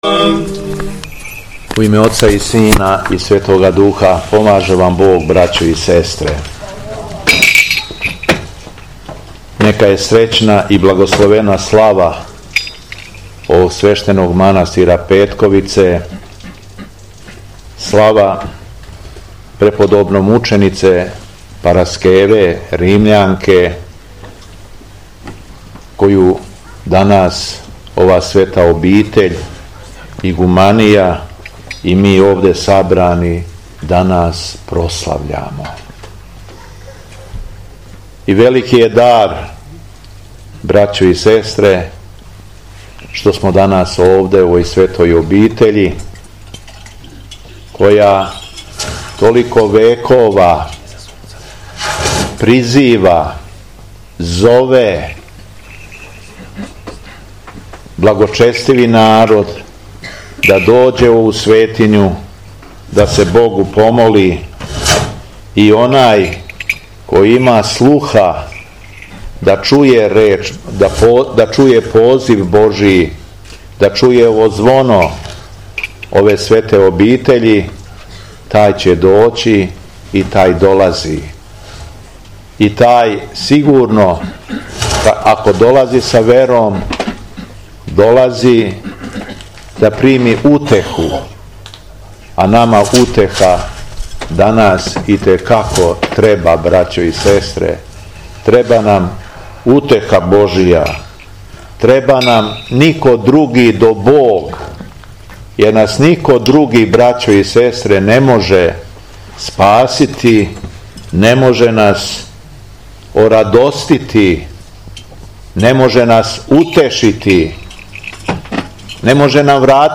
СВЕТА ЛИТУРГИЈА У МАНАСТИРУ СВЕТЕ ПАРАСКЕВЕ У СТРАГАРИМА - Епархија Шумадијска
Беседа Његовог Високопреосвештенства Митрополита шумадијског г. Јована
Након прочитаног зачала из Светог Јеванђеља Митрополит се обратио верном народу надахнутом беседом